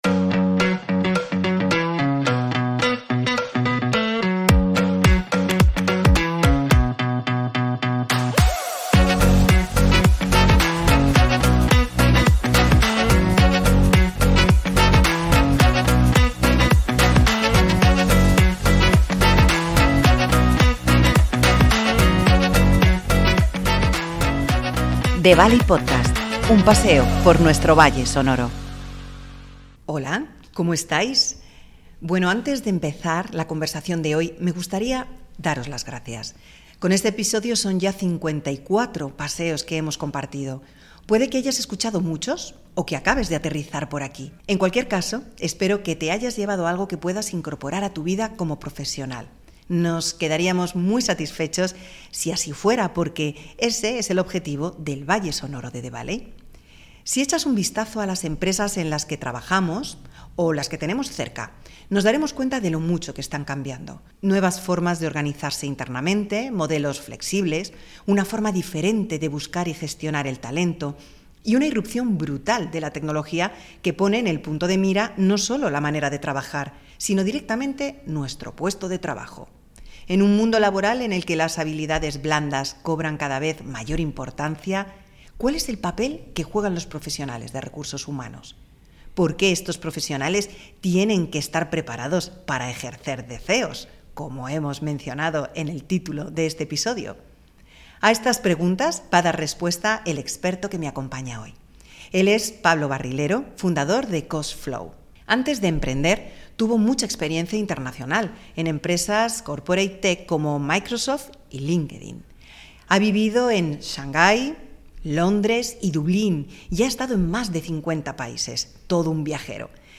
Un nuevo paseo para conversar sobre la transformación del rol del profesional de RRHH. Descubrimos cómo la inteligencia artificial y la tecnología están redefiniendo las habilidades necesarias, llevando a los HR a convertirse en auténticos CEOs en sus organizaciones. Hablaremos sobre la importancia de potenciar las soft skills y cómo estas se han vuelto cruciales para maximizar el impacto en el negocio.